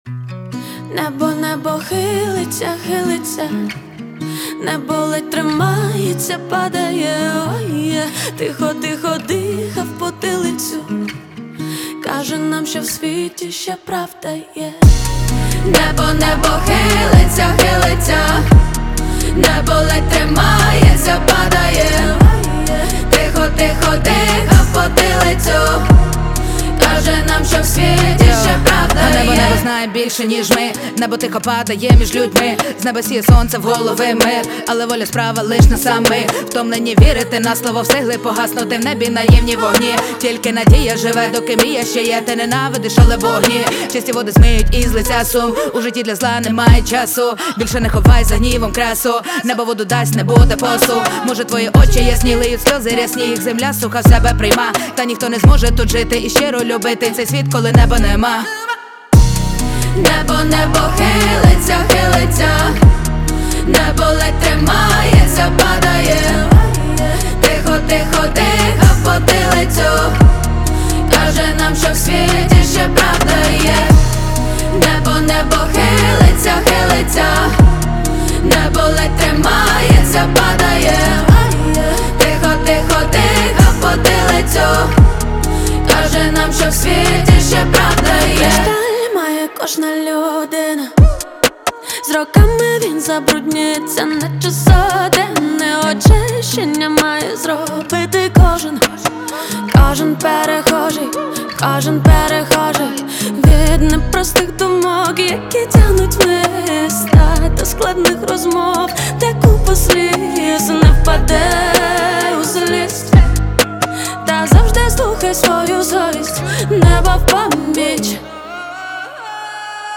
• Жанр: Українська музика